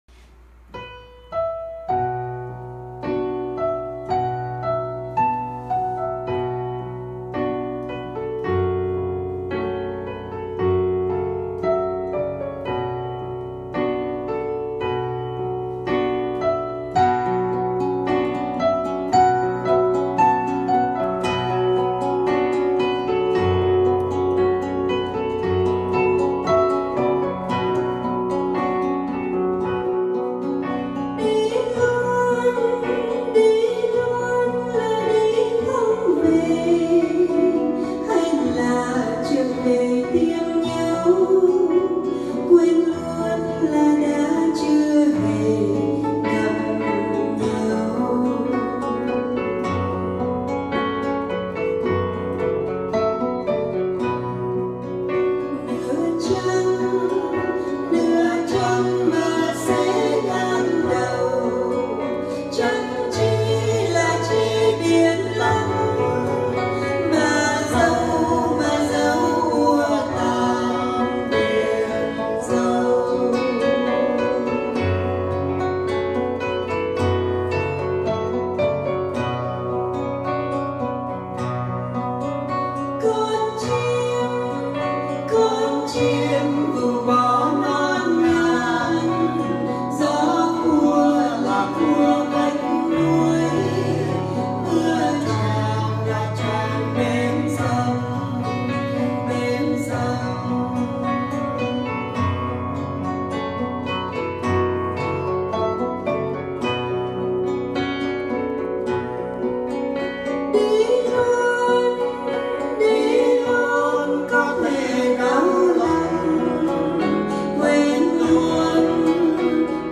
Đệm dương cầm